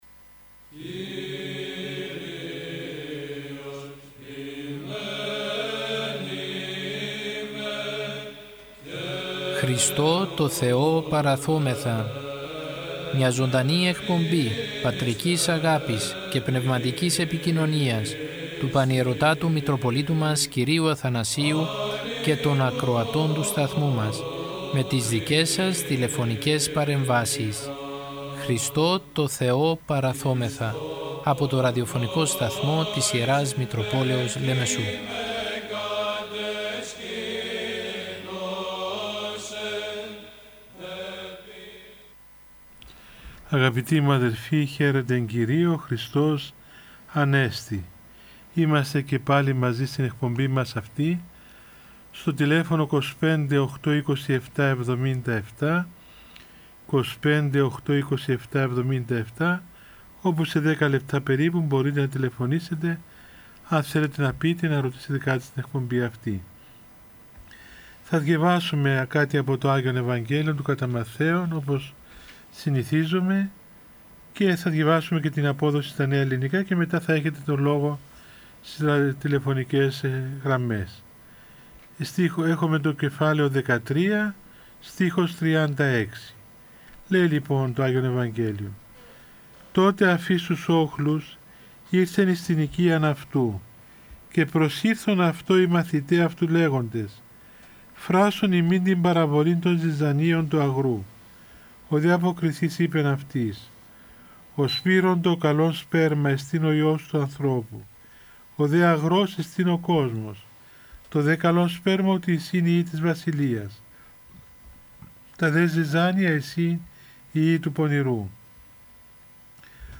Η Ιερά Μητρόπολη Λεμεσού, μετά το διάταγμα που εξέδωσε η Κυβέρνηση για την αντιμετώπιση της πανδημίας του κορωνοϊού, που περιορίζει τις μετακινήσεις και την προσέλευση των πιστών στους ναούς, θέλοντας να οικοδομήσει και να στηρίξει ψυχικά και πνευματικά όλους τους πιστούς παρουσιάζει καθημερινά ζωντανές εκπομπές με τον Πανιερώτατο Μητροπολίτη Λεμεσού κ. Αθανάσιο, με τίτλο «Χριστώ τω Θεώ παραθώμεθα».
Ο Πανιερώτατος απαντά στις τηλεφωνικές παρεμβάσεις των ακροατών του Ραδιοφωνικού Σταθμού της Ι. Μ. Λεμεσού και απευθύνει λόγο παρηγορητικό, παραμυθητικό και ποιμαντικό.